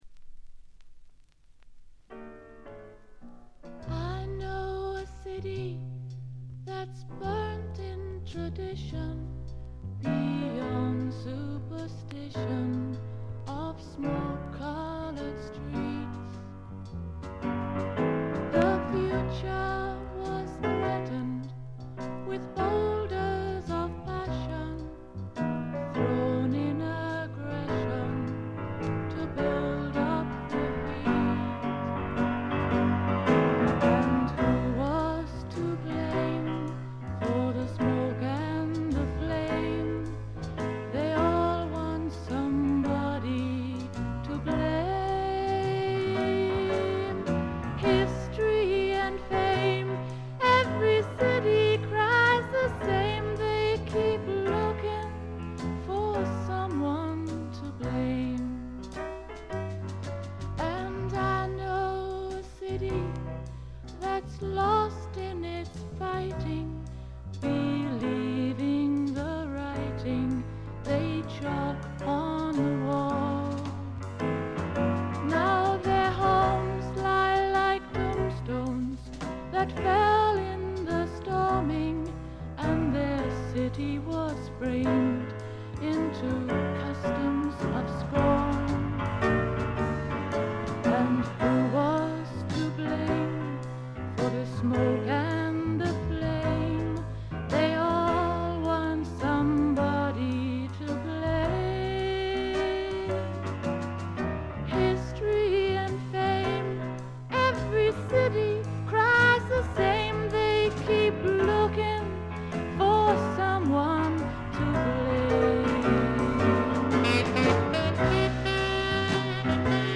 曲はすべて自作で、いかにも英国の女性シンガー・ソングライターらしいポップながら陰影のある曲が並びます。
試聴曲は現品からの取り込み音源です。
vocal, acoustic guitar